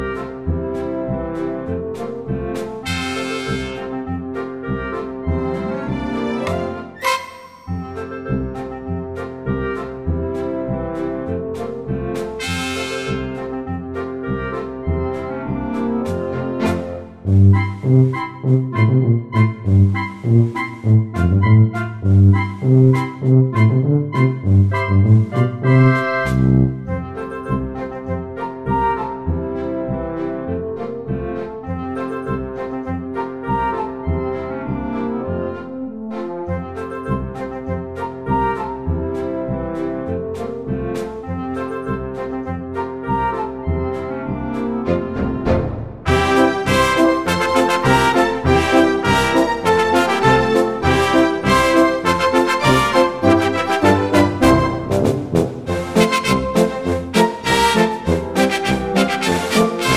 no Backing Vocals Musicals 3:11 Buy £1.50